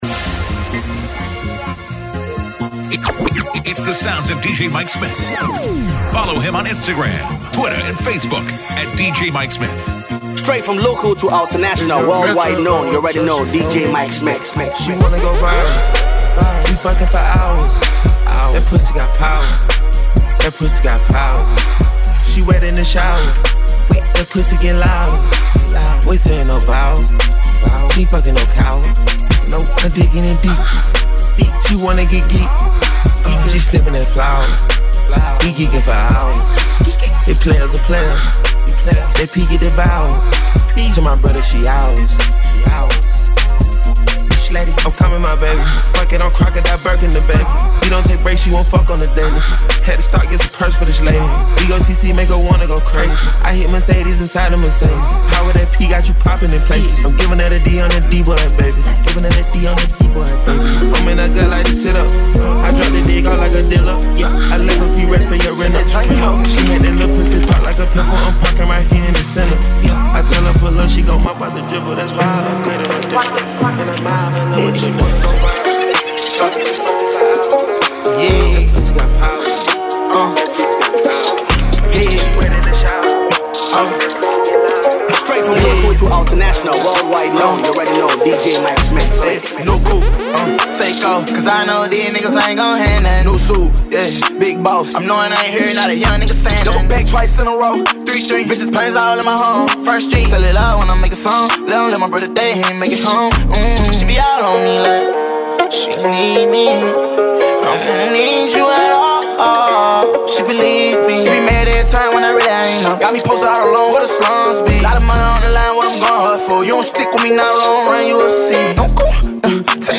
DJ mixtape